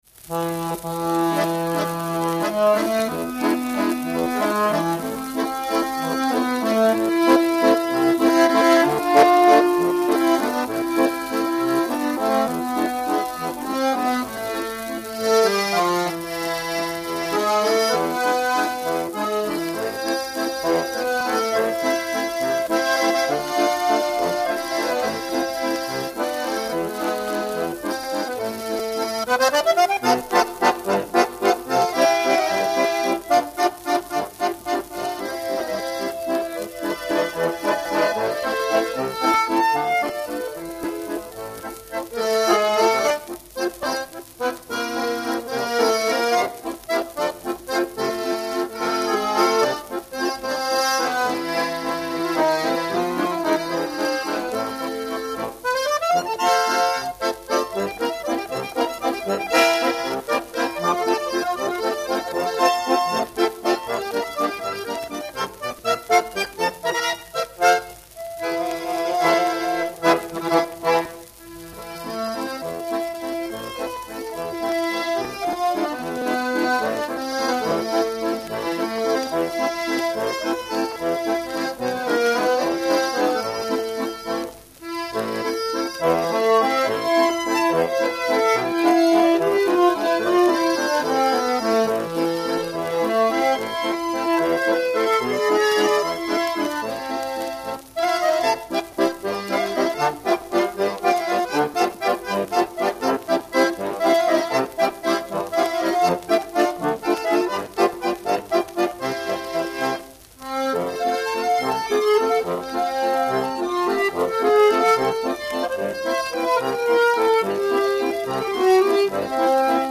Трио баянистов